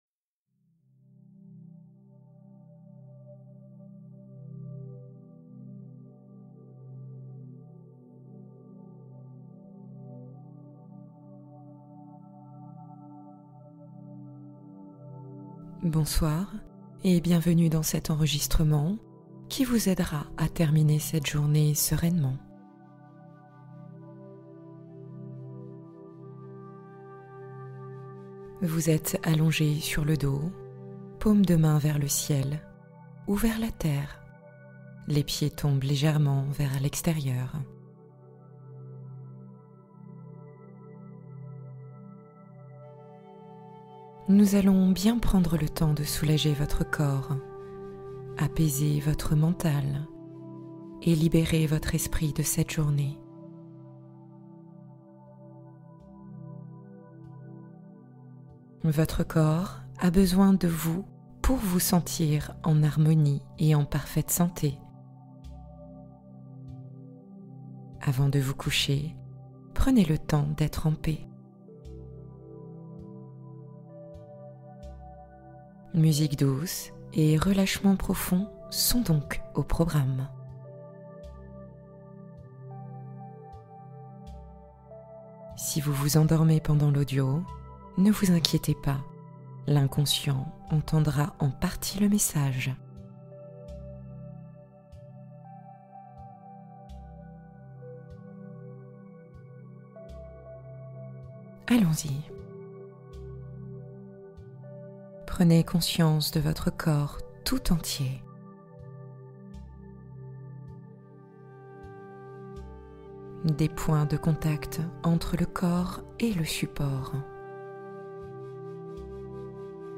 Sommeil naturel : hypnose immersive aux sons apaisants